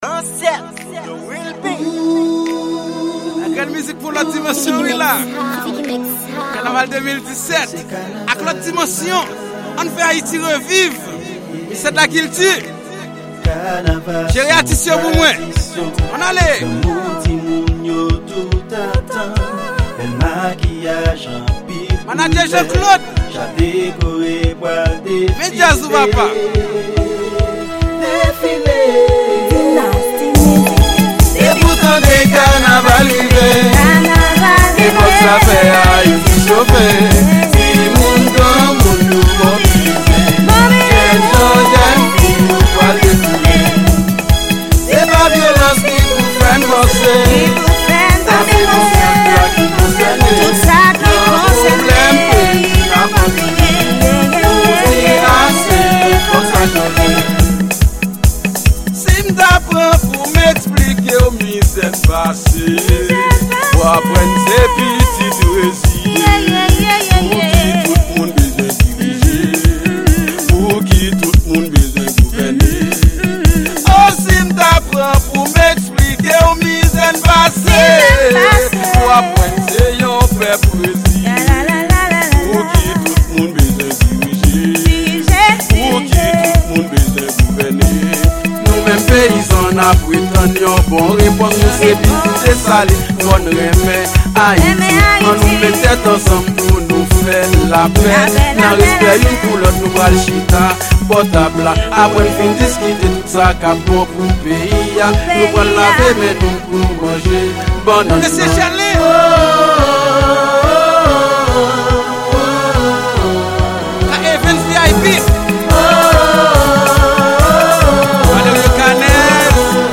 Genre: K-naval.